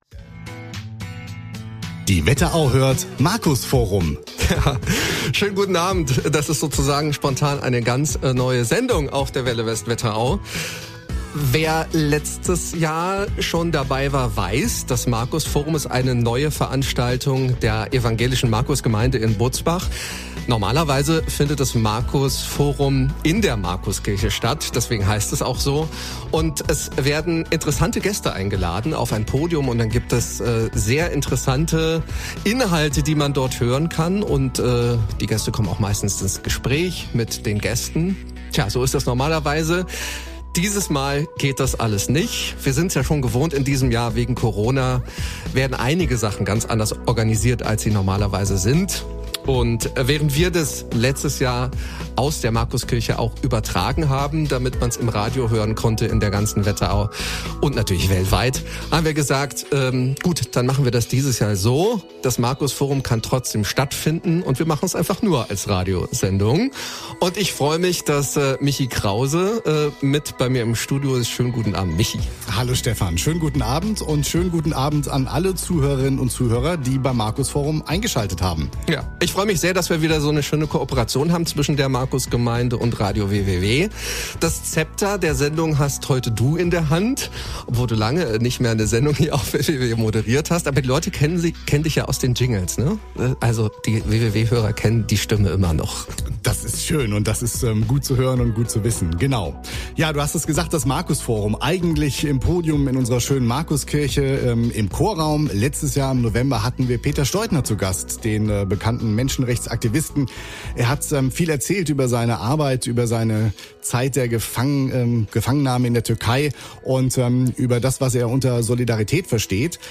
2. Markusforum: “Wachstumswende” live am 03.09.2020, 19:30
Zum zweiten Mal veranstaltet die Evangelische Markusgemeinde Butzbach in Kooperation mit Radio WeWeWe das “Markusforum”.